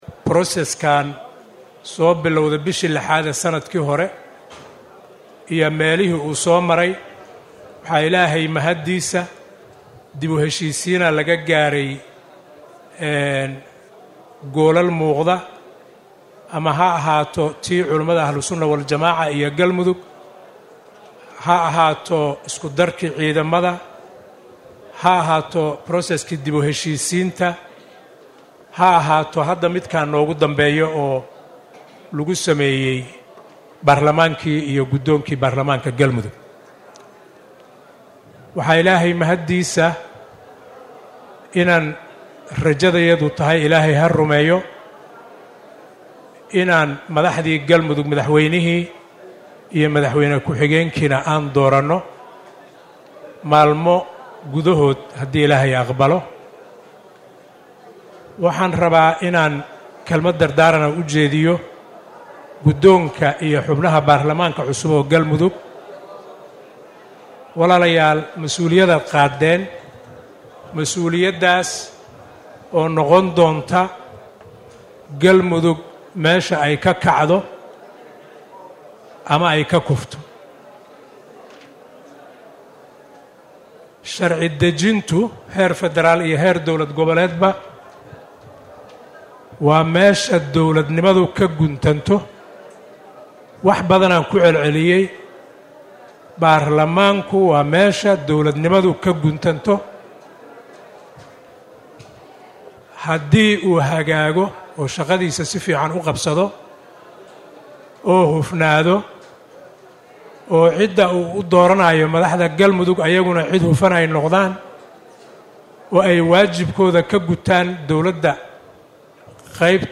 Wasiirka Arrimaha Gudaha, Federaalka iyo dib u heshiisiinat Xukuumadda Soomaaliya Cabdi Maxamed Sabriye oo qudbad ka jeediyay gabogabadii doorashada guddoonka Barlamaanka Galmudug ayaa ka hadlay hannaankii uu soo maray dhismaha Galmudug.
CODKA-WASIIRKA-ARRIMAHA-GUDAHA-.mp3